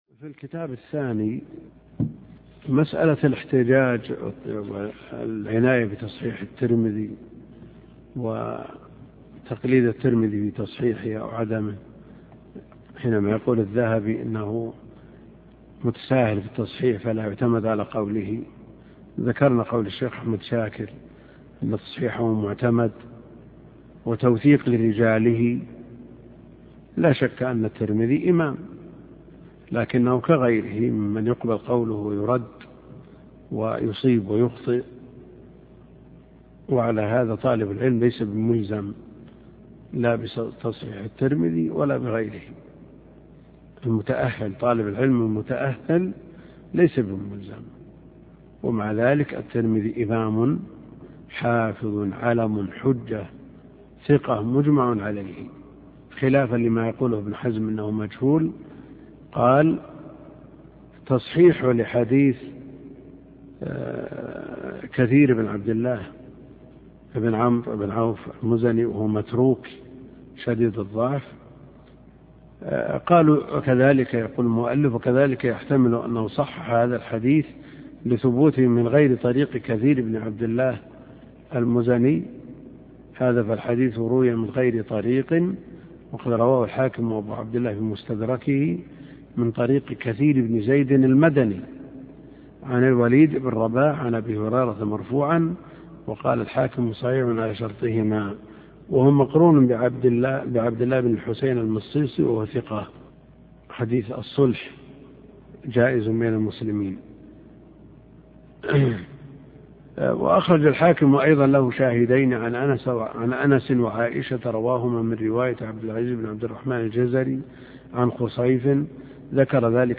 الدرس (13) شرح تنقيح الأنظار لابن الوزير